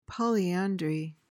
PRONUNCIATION:
(POL-ee-an-dree)